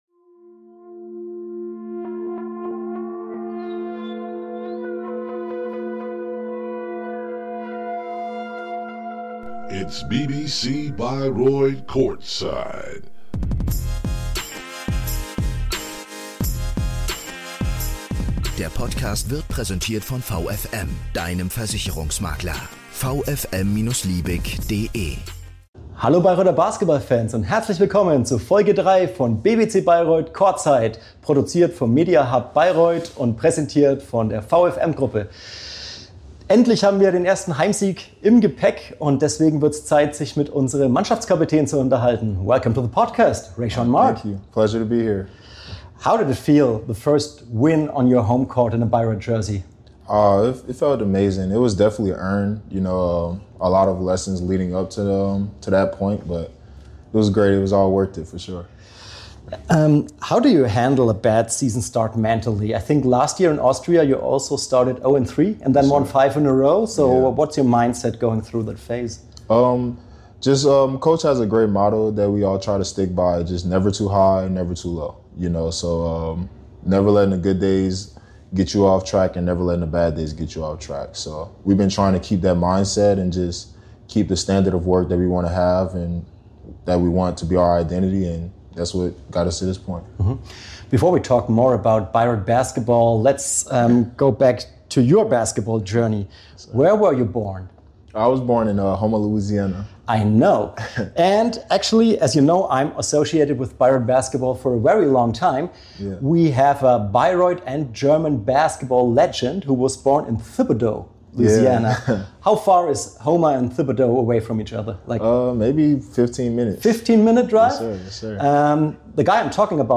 Dazu gibt’s spannende Einblicke in den Teamspirit, Community-Fragen der Fans und ein paar überraschende Geschichten aus seiner Karriere. Ein ehrliches, sympathisches Gespräch über Motivation, Verantwortung und den Traum vom Basketball.